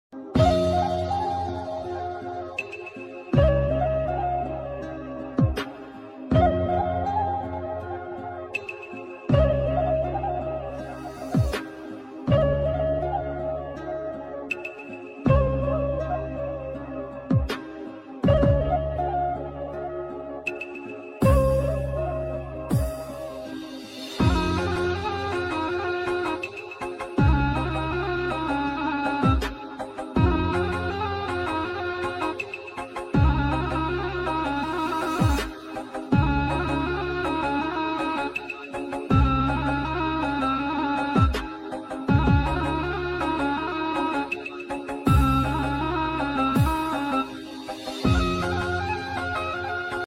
𝑺𝑨𝑫 𝑩𝑨𝑪𝑲𝑹𝑶𝑼𝑵𝑫 𝑴𝑼𝑺𝑰𝑪
𝑆𝐿𝑂𝑊𝐸𝐷+[𝑅𝐸𝑉𝐸𝑅𝐵]𝐴𝑁𝐷 𝐵𝐴𝐴𝑆𝐵𝑂𝑂𝑆𝑇𝐸𝐷